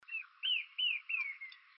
Arapaçu-de-cerrado (Lepidocolaptes angustirostris)
Nome em Inglês: Narrow-billed Woodcreeper
Fase da vida: Adulto
Localidade ou área protegida: Reserva Ecológica Costanera Sur (RECS)
Condição: Selvagem
Certeza: Gravado Vocal